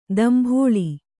♪ dambhōḷi